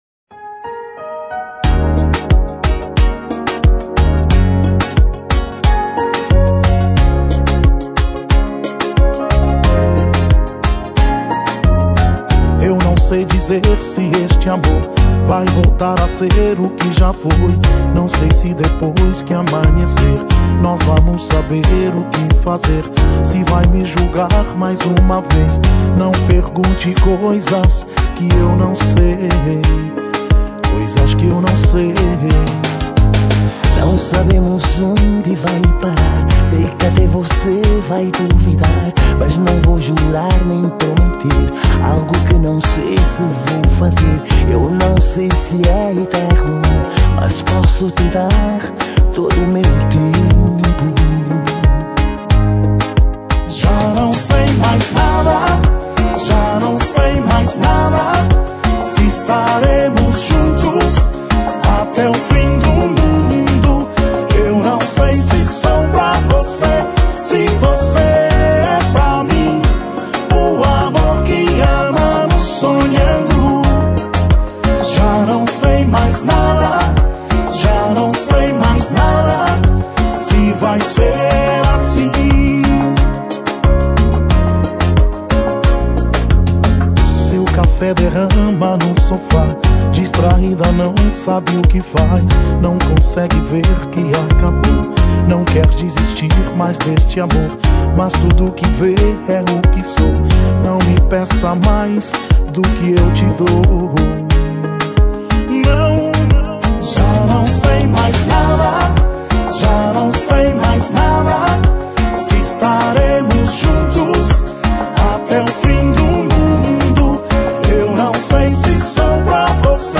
Kizomba Para Ouvir: Clik na Musica.